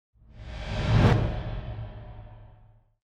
Звуки переходов
Шокирующий эффект смены перехода